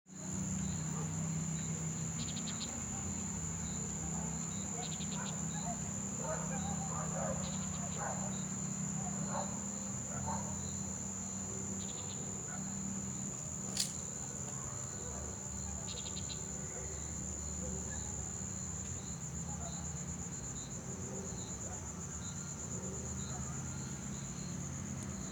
Añapero Querequeté (Chordeiles gundlachii)
Sobrevuela en la plaza vocalizando
Nombre en inglés: Antillean Nighthawk
Localización detallada: Plaza Independencia
Condición: Silvestre
Anapero-Querequete.mp3